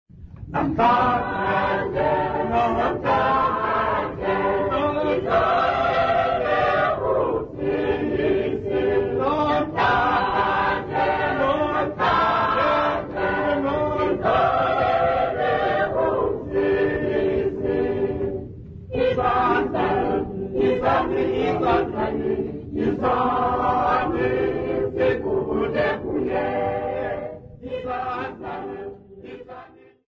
Workshop participants
Church music South Africa
Hymns, Xhosa South Africa
Folk music South Africa
Africa South Africa Zwelitsha, Eastern Cape sa
field recordings
Xhosa composition workshop